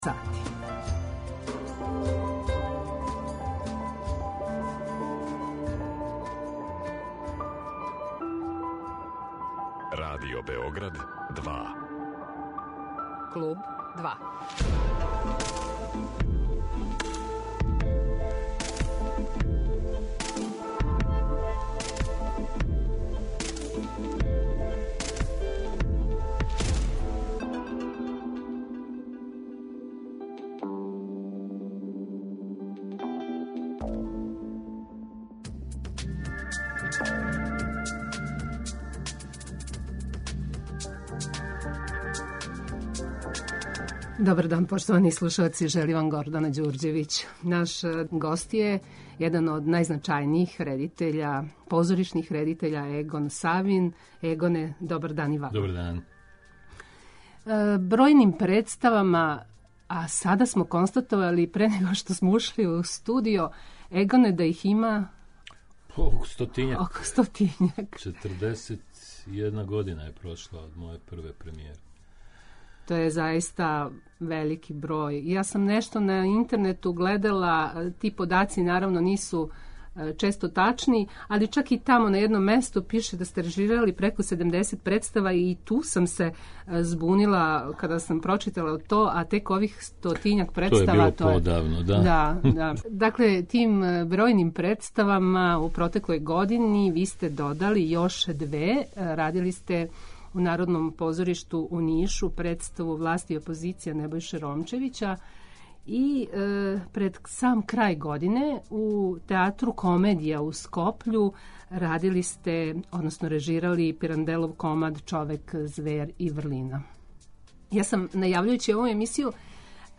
Гост - Егон Савин